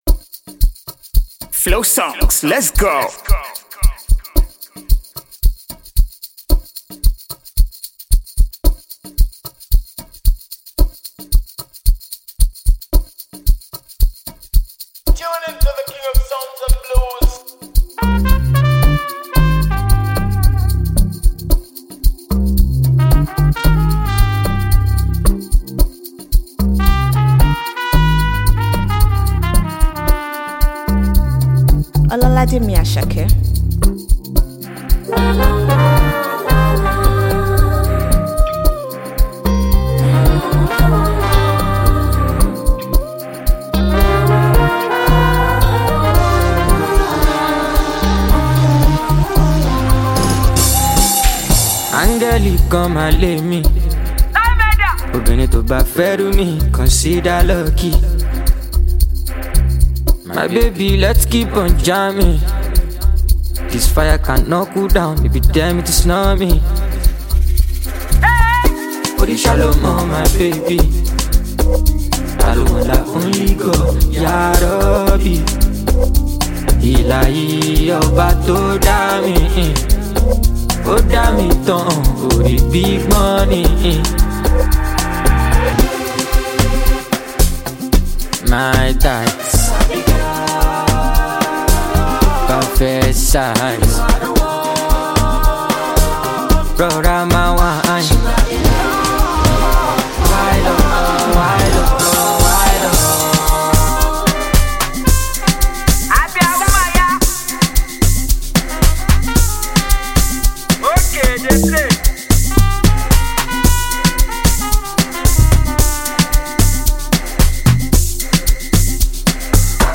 It is a catchy and lively melody.
AFROPOP and Afrobeat